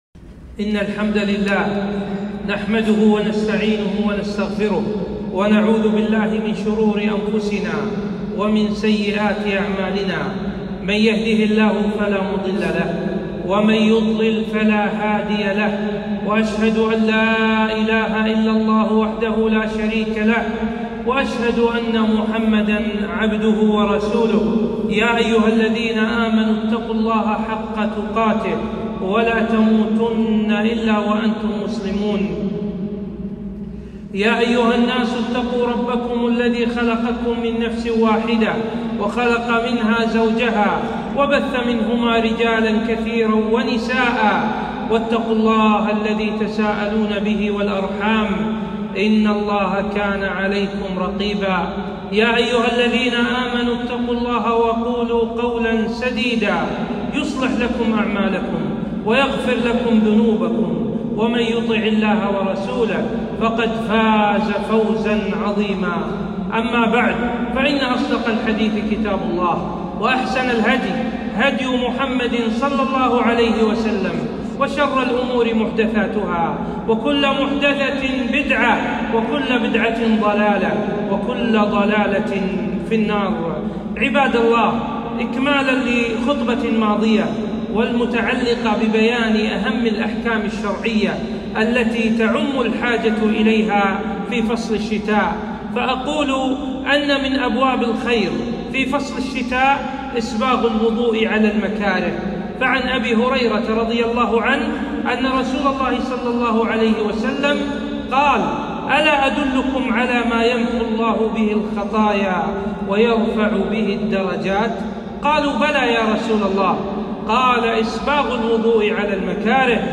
خطبة - أحكام الشتاء 2